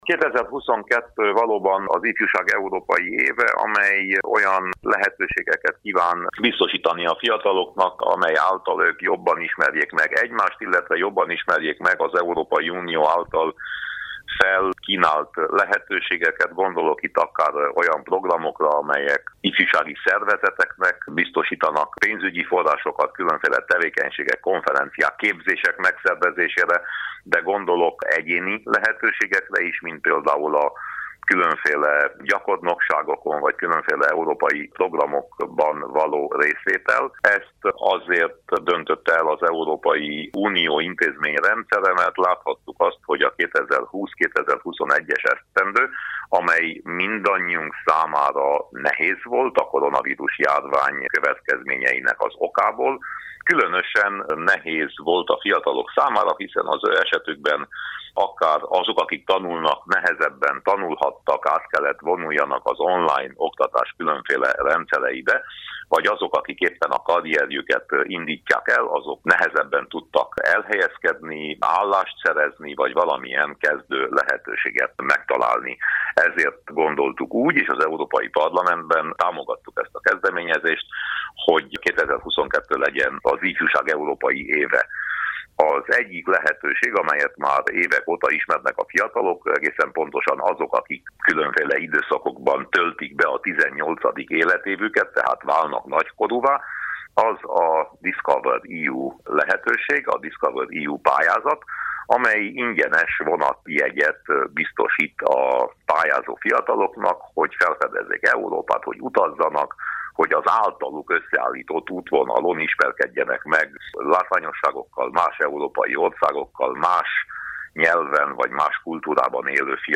A részletekről Winkler Gyula európai parlamenti képviselőt kérdeztük.